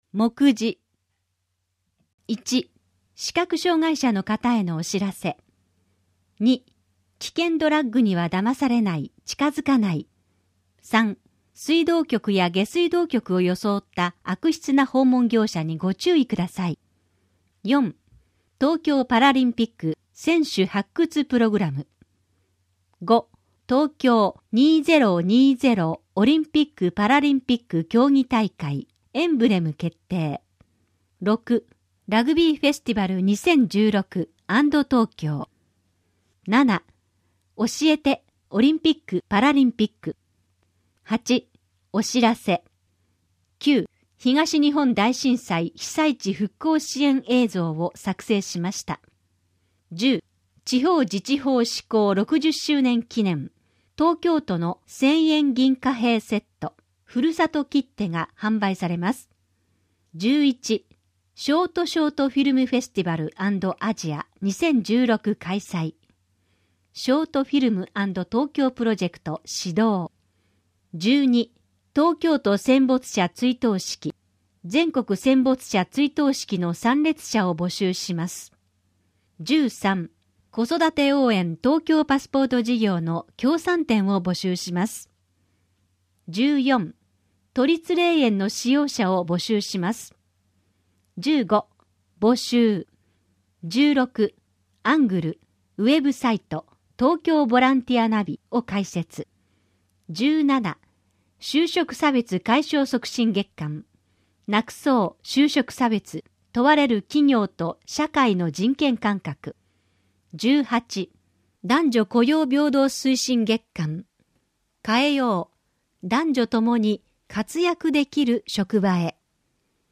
広報東京都 音声版」は、視覚に障害のある方を対象に「広報東京都」の記事を再編集し、音声にしたものです。